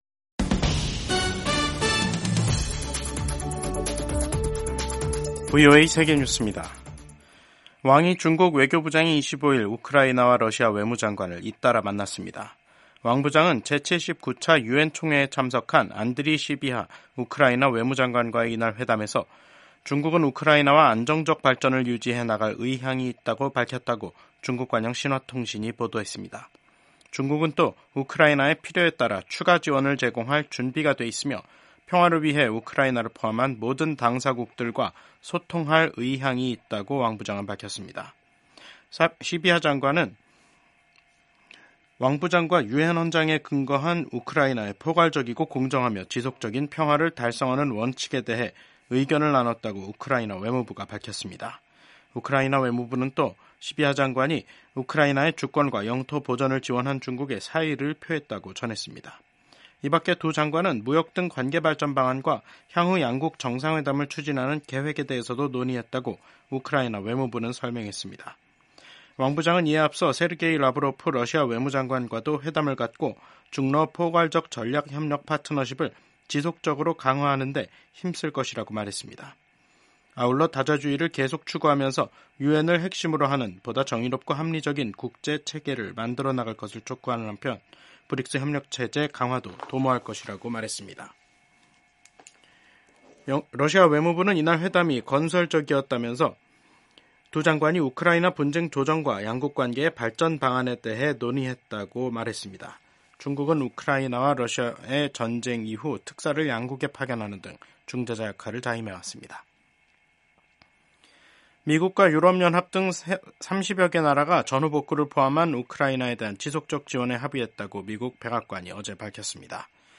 세계 뉴스와 함께 미국의 모든 것을 소개하는 '생방송 여기는 워싱턴입니다', 2024년 9월 26일 저녁 방송입니다. 미국과 프랑스 등 동맹국들이 이스라엘과 헤즈볼라 사이 21일 휴전을 촉구했습니다. 미국 공화당 대선 후보인 도널드 트럼프 전 대통령은 자신을 겨냥한 암살 시도의 배후가 이란일 수도 주장했습니다. 경제협력개발기구(OECD)가 중간 경제 전망 보고서에서 올해 세계 경제 성장률을 3.2%로 전망했습니다.